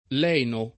kU#ndo S1ffLa b0rea da kkU%lla gU#n©a ond $ ppLu ll$no] (Dante) — lene, forma dòtta, con -e- aperta da sempre; leno, forma pop. (lat. lenis con -e- lunga), attestata pure con -e- aperta dal ’500, ma certo in origine con -e- chiusa — cfr. allenare